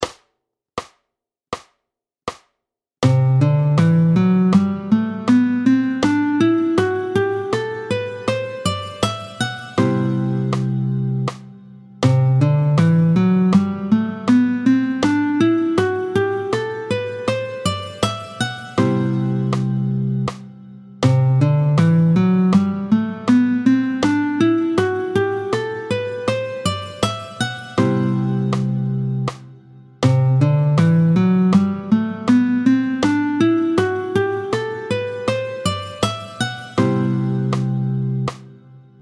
Le mode (et le doigté IV) : do ré mi fa# sol la si do, est appelé le Lydien.
27-04 Le doigté du mode de Do lydien, tempo 80
27-04-Do-Lydien.mp3